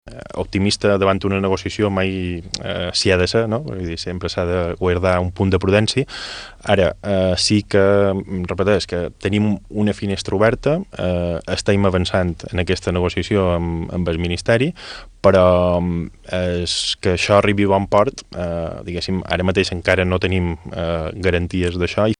Són declaracions a la secció d’economia de l’Informatiu Vespre d’IB3 Ràdio.